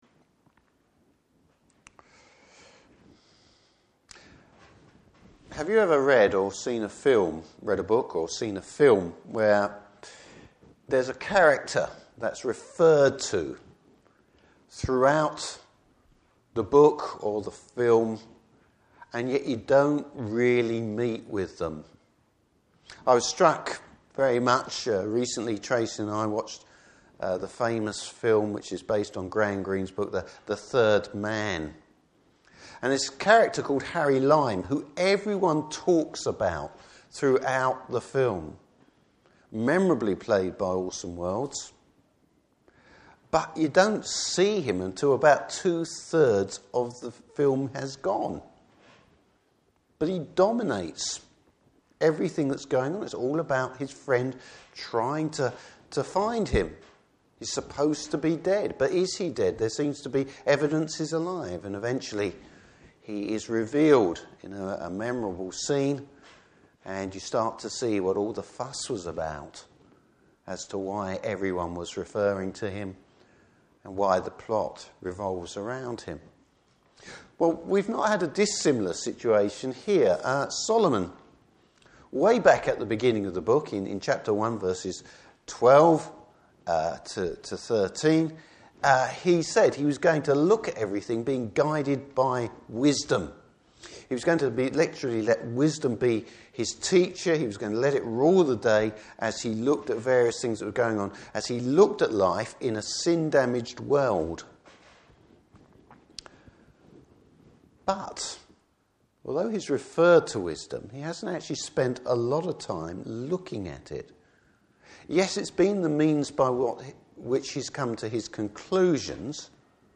Service Type: Morning Service Bible Text: Ecclesiastes 7.